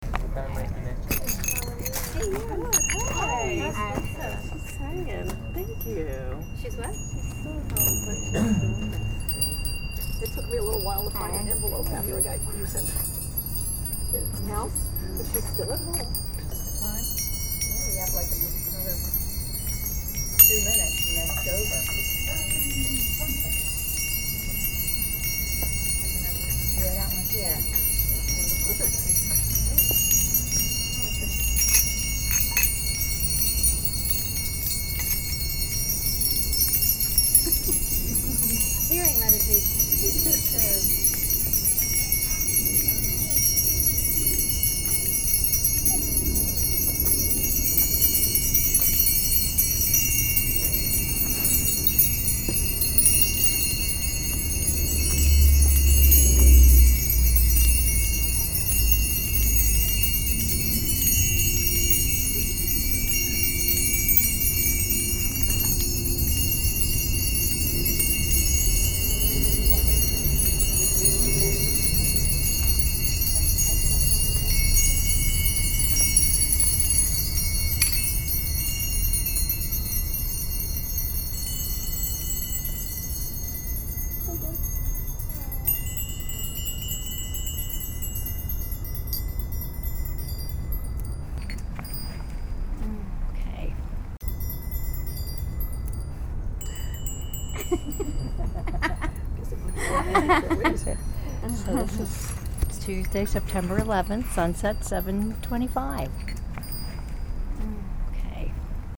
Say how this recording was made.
2008 was the inaugural year in an ongoing, aspirational project dedicated to the observation of the sun every time it crosses the horizon and to sharing the awareness of that moment with others while ringing bells. Meditation with Mission Dharma